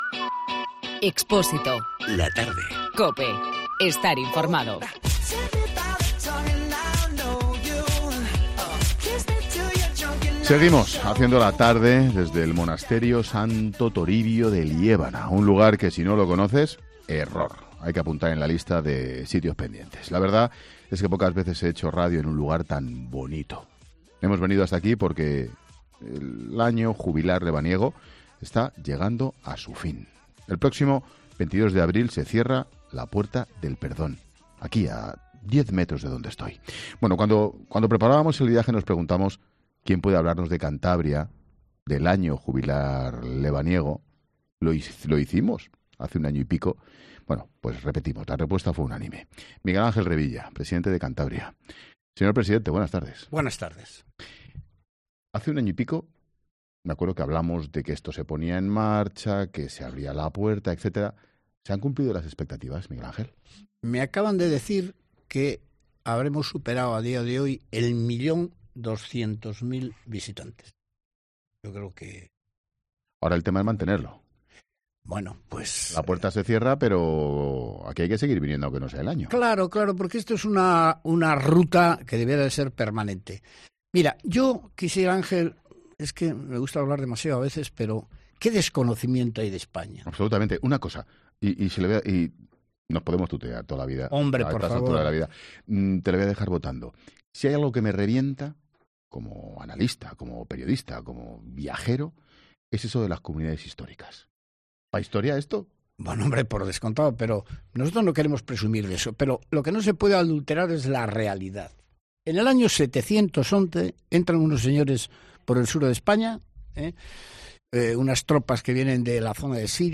Ángel Expósito entrevista a Miguel Ángel Revilla desde el Monasterio de Santo Toribio de Liébana